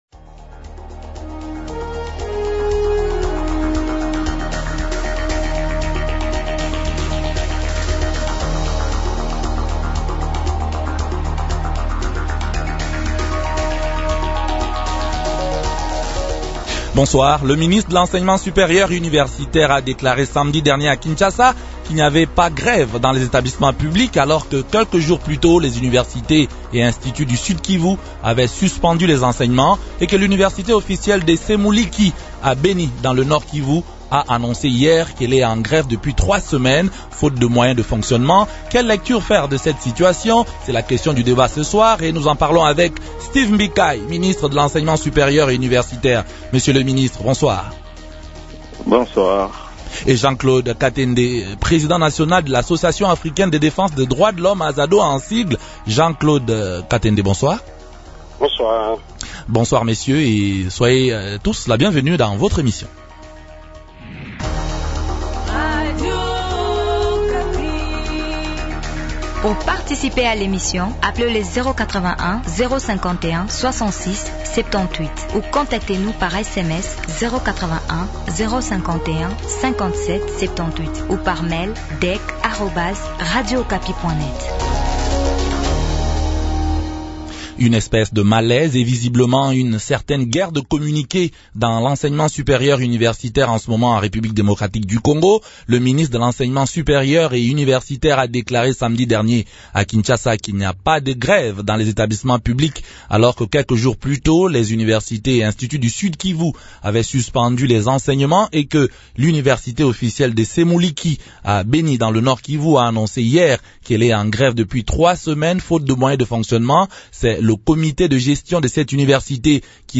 Invités : Steve Mbikayi, Ministre de l’Enseignement supérieur et universitaire.